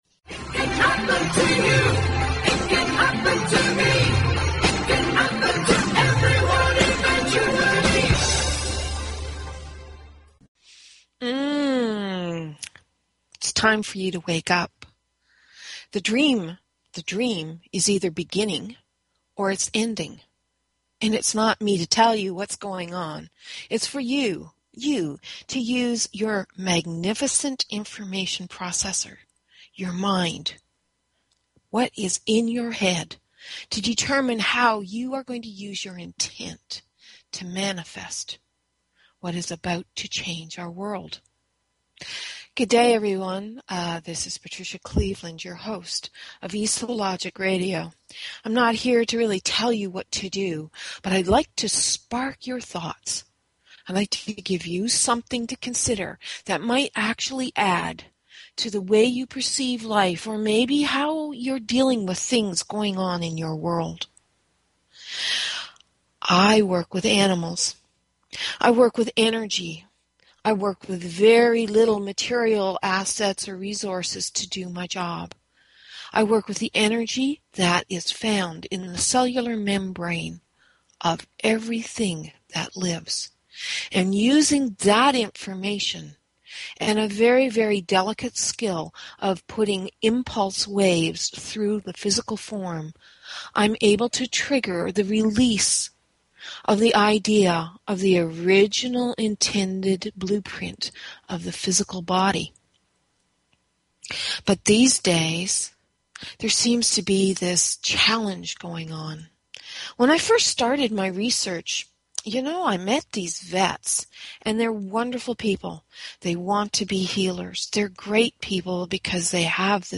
Talk Show Episode, Audio Podcast, eSO_Logic_Radio and Courtesy of BBS Radio on , show guests , about , categorized as
Courtesy of BBS Radio